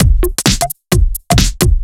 OTG_Kit 2_HeavySwing_130-A.wav